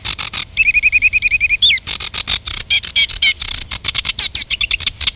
Phragmite des joncs, acrocephalus schoenobaenus
phragmite.mp3